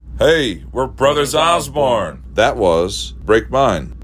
LINER Brothers Osborne (Break Mine) 5